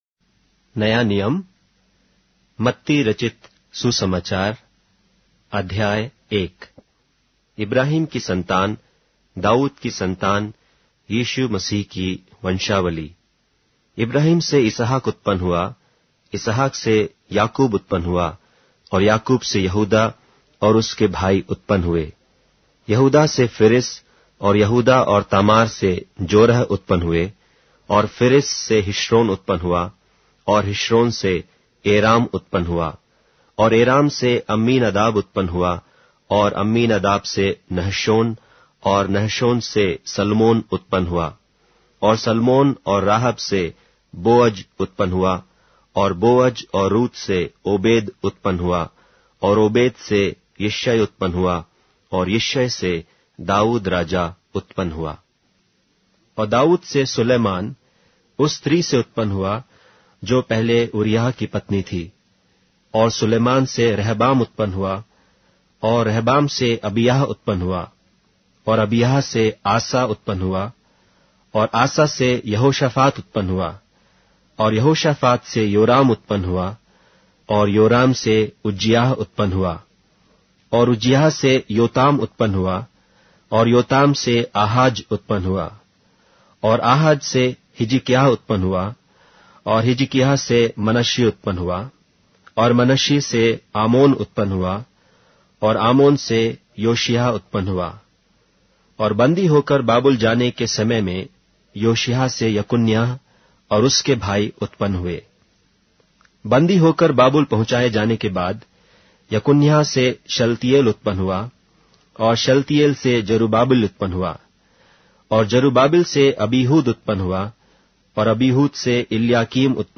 Hindi Audio Bible - Matthew 28 in Ervbn bible version